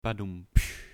Rimshot